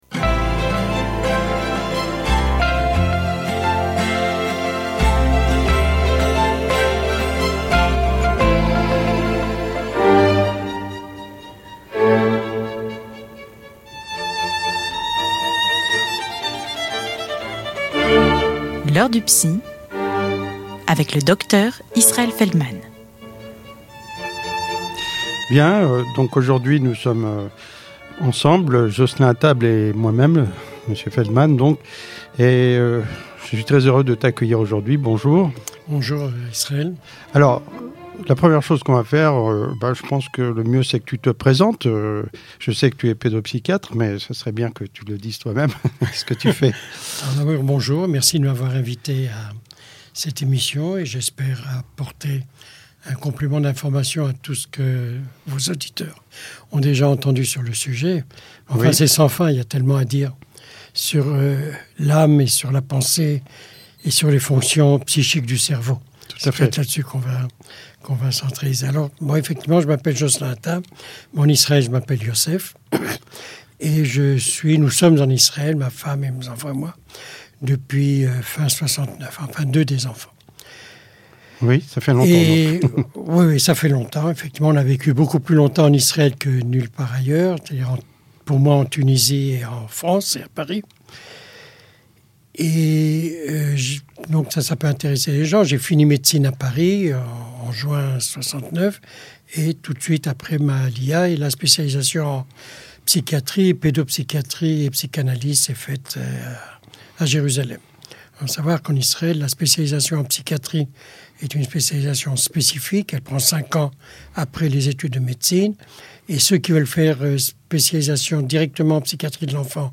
Émissions radio